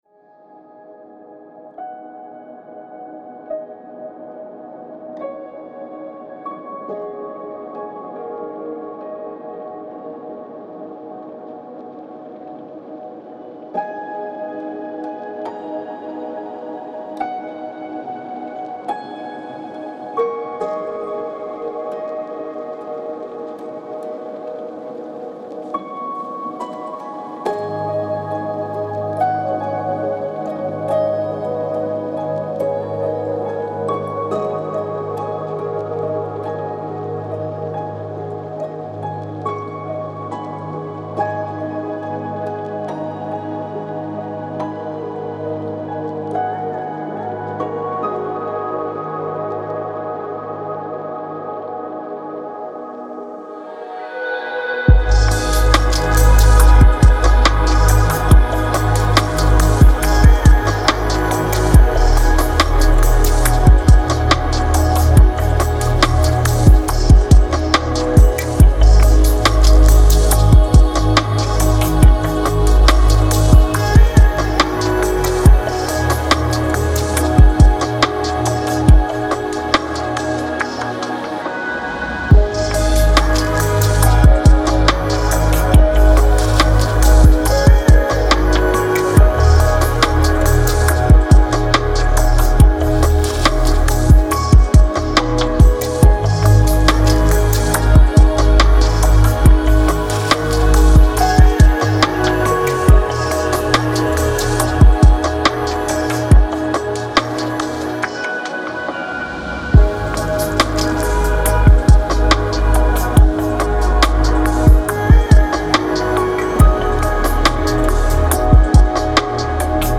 это атмосферная композиция в жанре электронного музыки